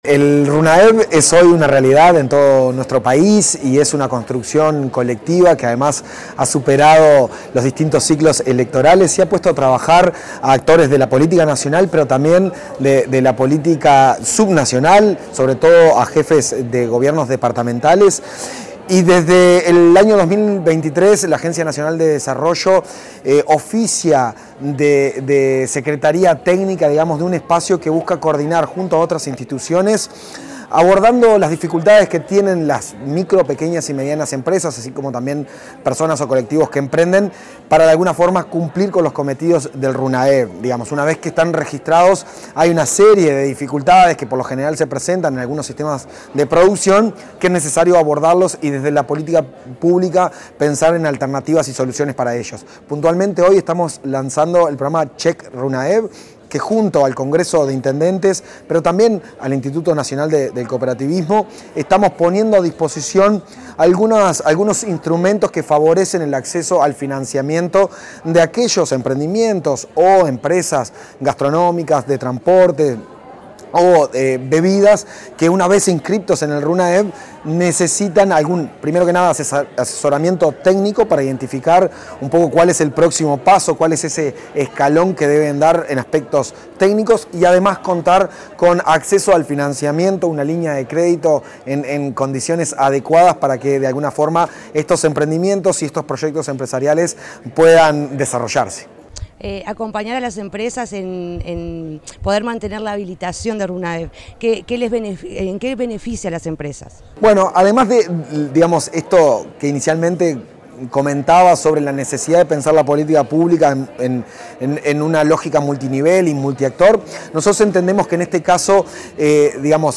Declaraciones del presidente de la Agencia Nacional de Desarrollo, Juan Ignacio Dorrego